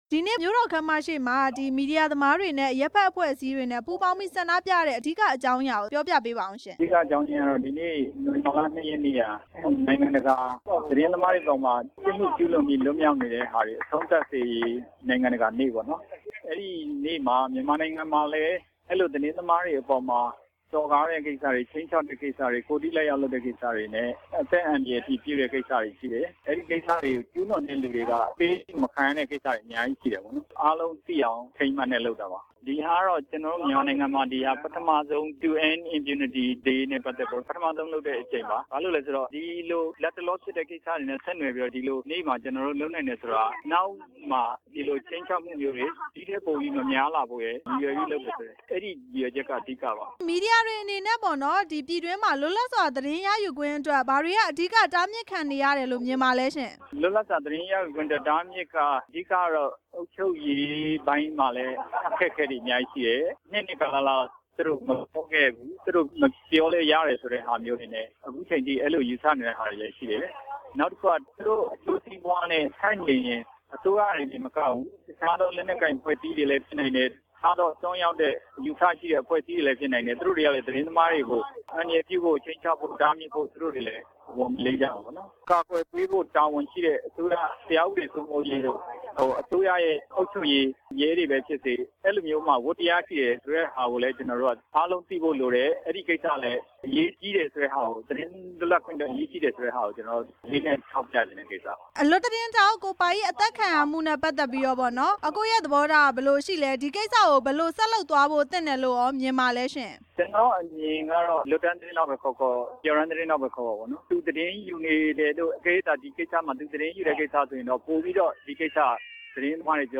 သတင်းသမားတွေအပေါ် ဖိနှိပ်ချုပ်ချယ်မှု မေးမြန်းချက်